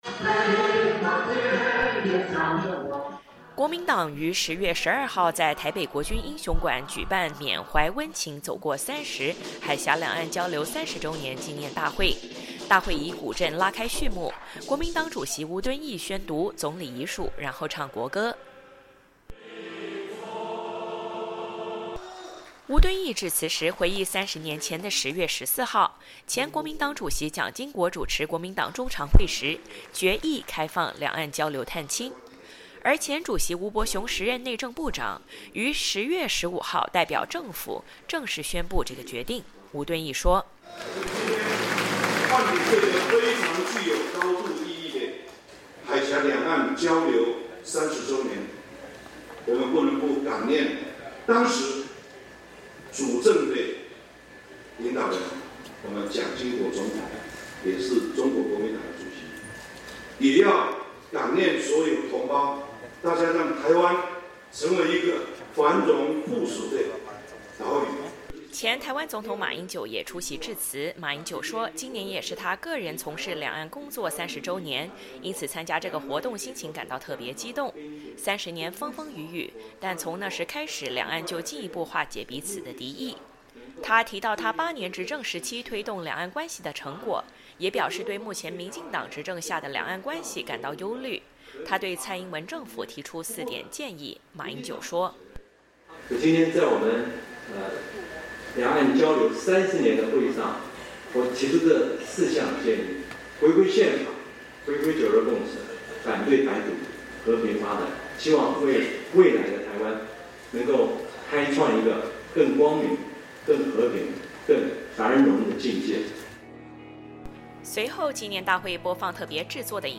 国民党于10月12号在台北国军英雄馆举办“缅怀温情，走过30”海峡两岸交流30周年纪念大会。大会以鼓陣拉開序幕，国民党主席吴敦义宣读“总理遗嘱”，然后唱国歌。
接下来，歌手带领在场的国民党员、老兵等人高唱当年被老兵唱响的思乡歌曲“母亲您在何方”。
最后，国民党主席吴敦义率领全体高唱“中华民国颂”，“两岸交流30周年纪念大会” 圆满结束。